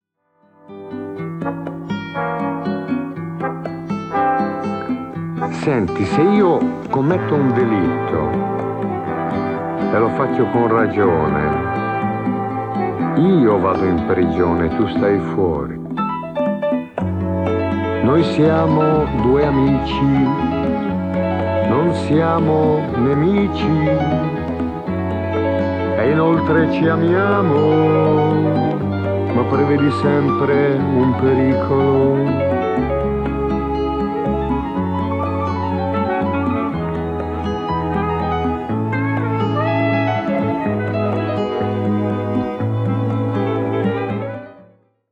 voce solista